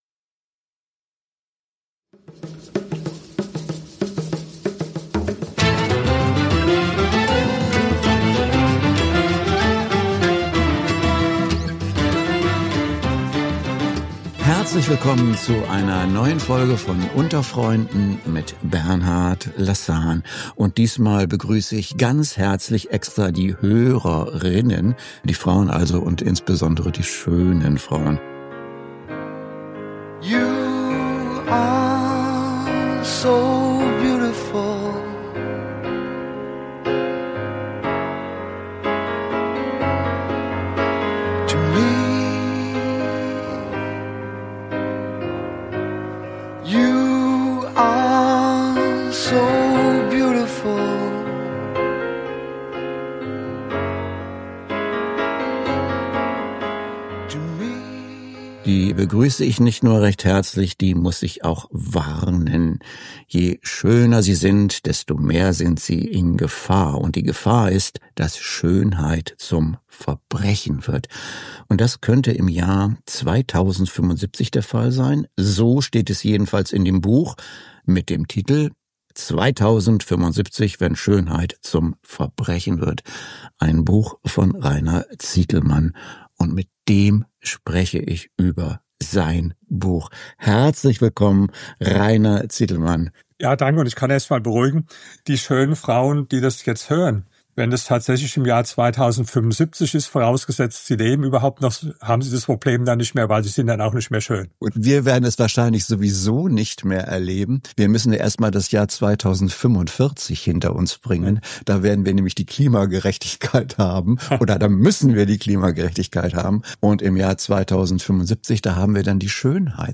Episode #247 - Interview bei Kontrafunk zu 2075 - Wenn Schönheit zum Verbrechen wird